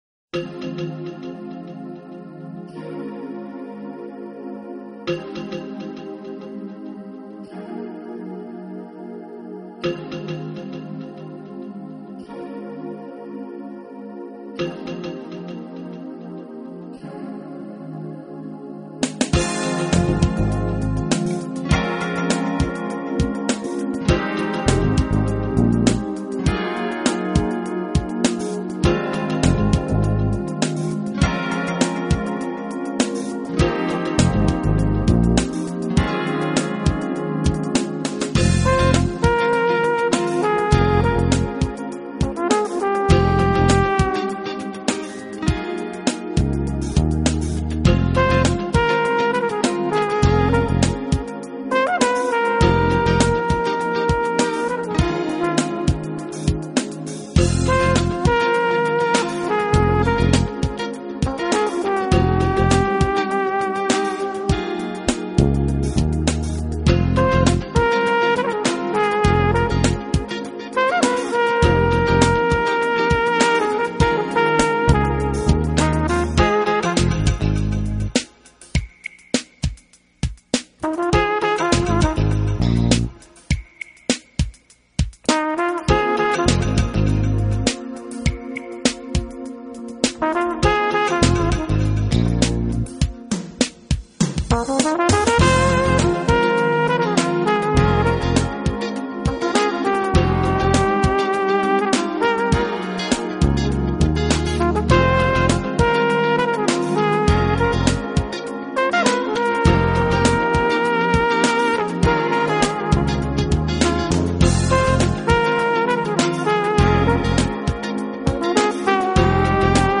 【爵士小号】
音乐类型:  Smooth Jazz